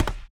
Add footstep sounds
stepstone_8.wav